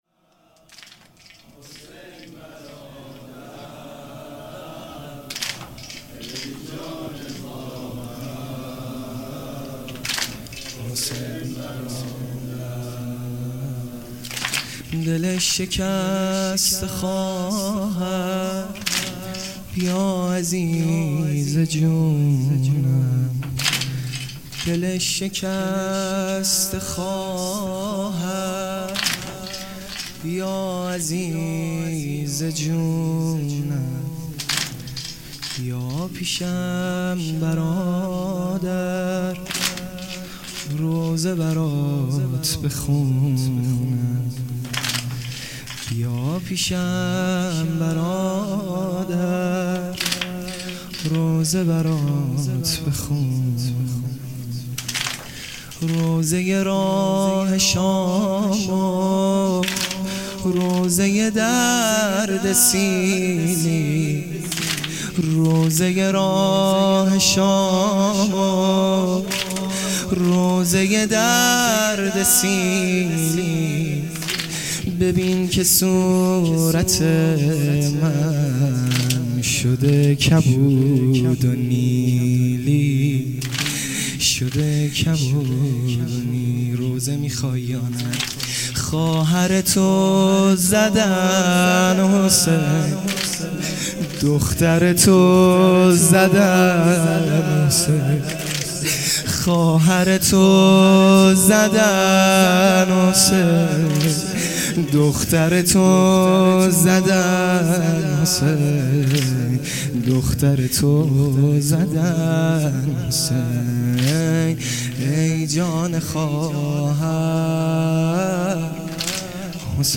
شهادت حضرت رقیه س | شب اول